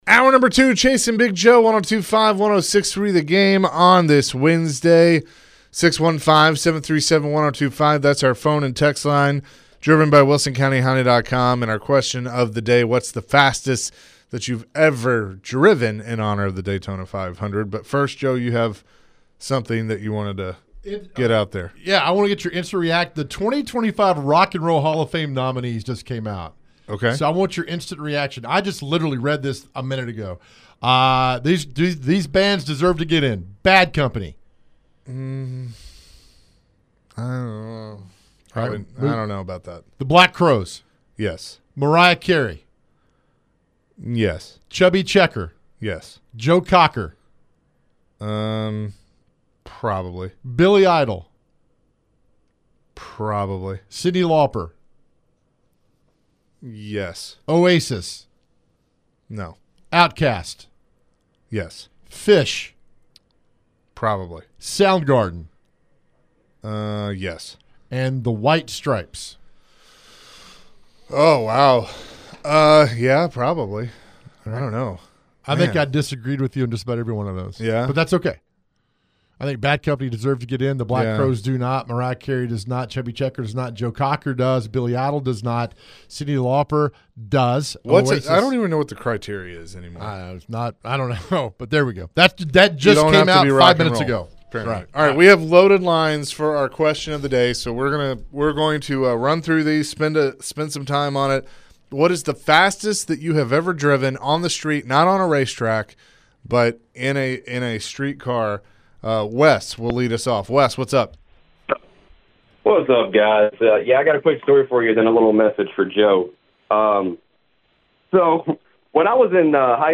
The guys got to some phone calls about their popular question of the day.